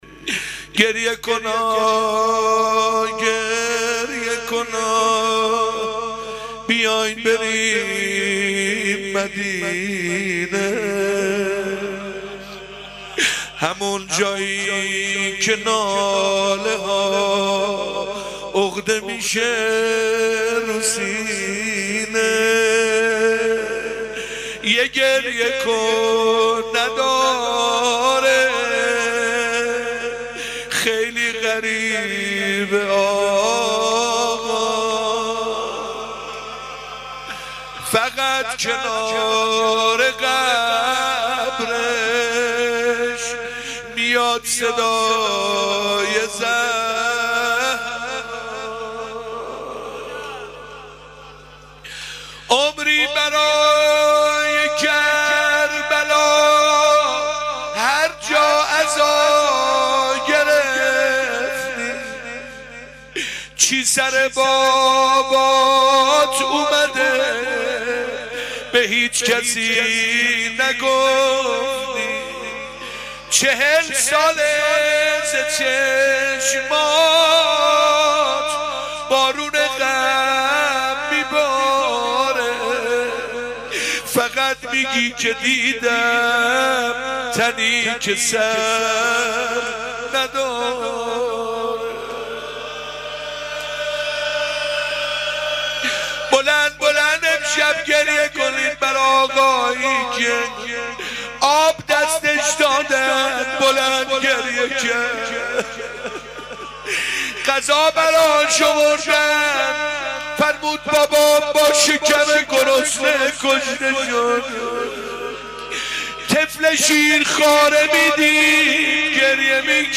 shab 4_ 2 ghazal , rozeh emam sajad.mp3